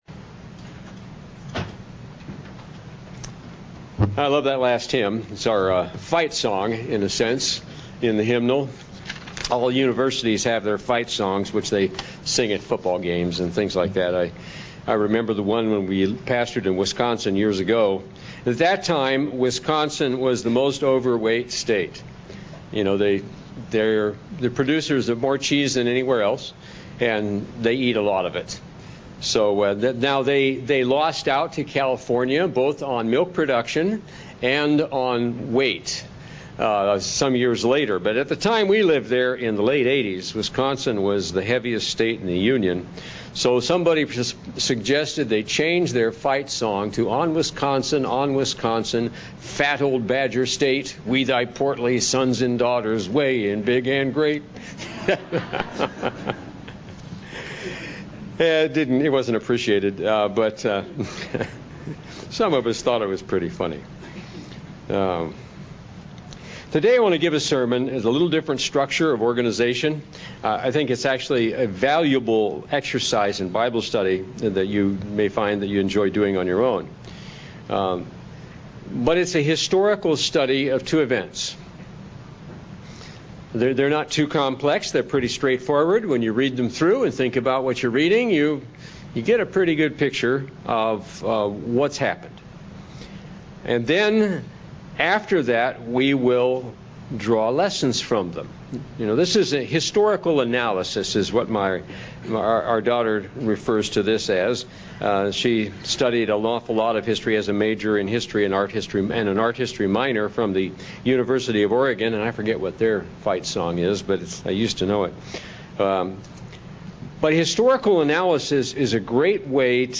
Which are you? history Sabbath Services Studying the bible?
Given in North Canton, OH